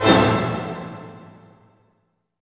Index of /90_sSampleCDs/Roland L-CD702/VOL-1/HIT_Dynamic Orch/HIT_Orch Hit min
HIT ORCHM0DR.wav